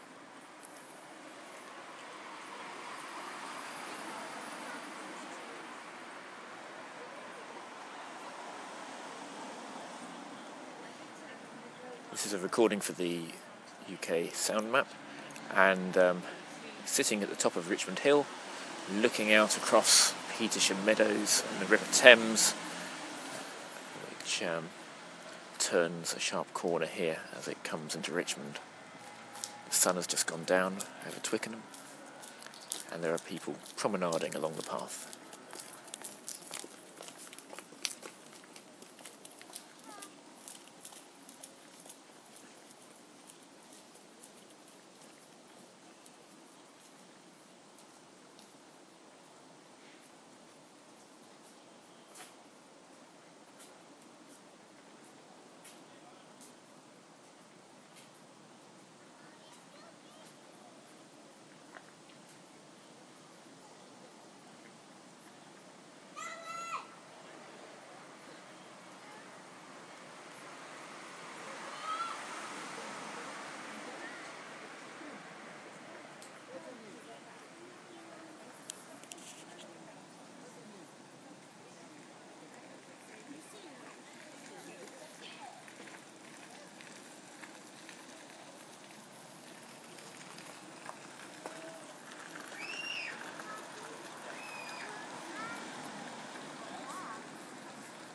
Richmond Hill at dusk - for uksm